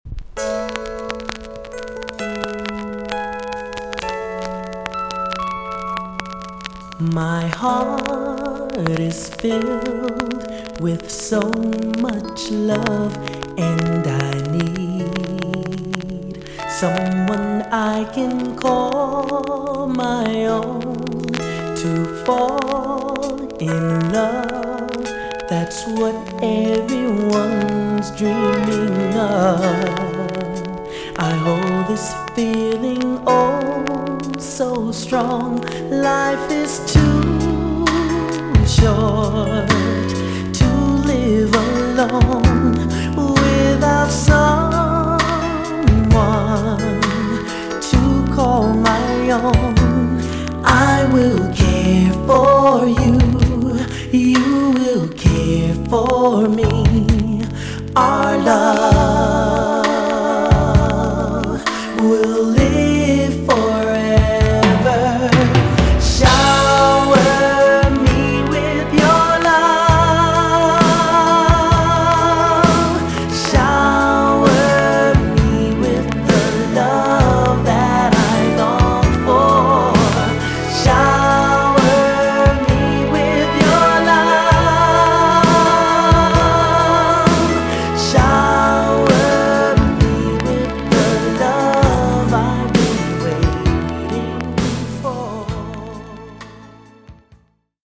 VG ok 全体的にチリノイズが入ります。
WICKED SLOW JAM TUNE!!